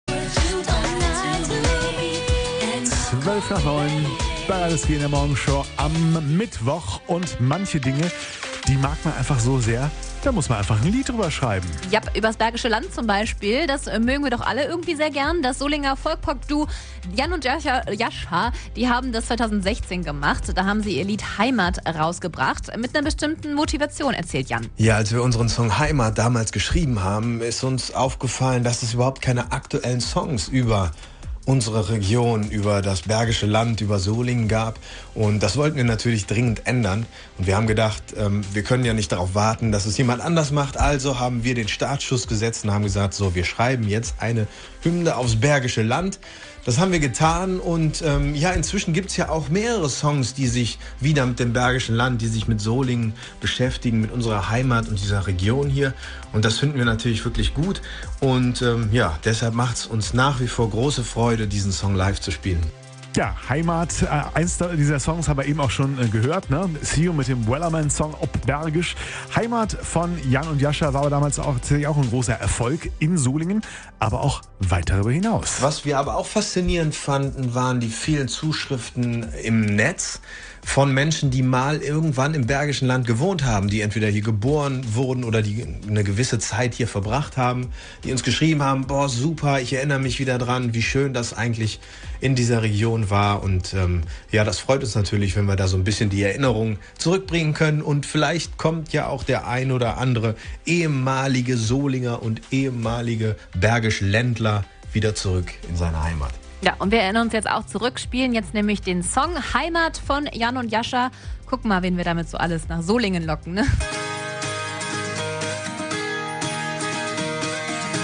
Folk Pop Duo